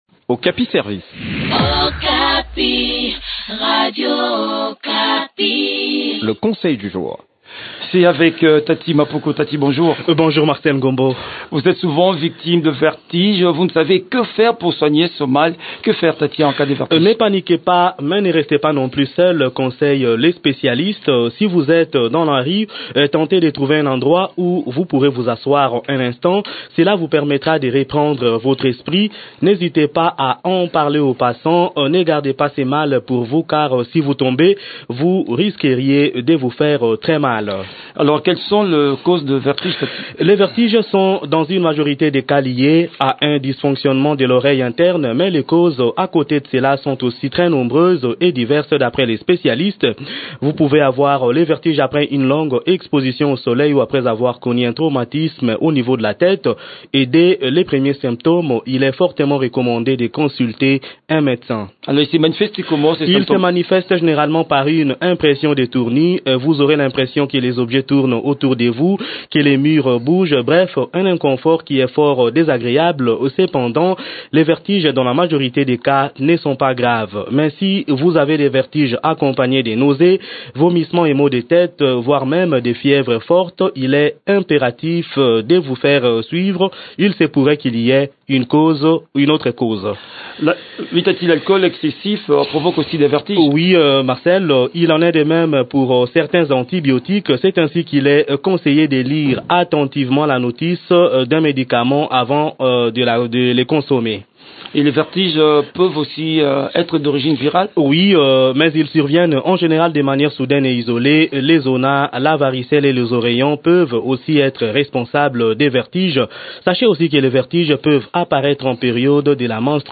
Découvrons des astuces dans cette chronique